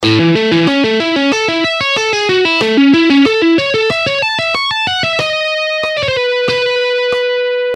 By combining the use of the pick and fingers, hybrid picking enables players to achieve a rich and dynamic sound that can elevate their playing to new heights.
Hybrid-Picking-Exercise-2.mp3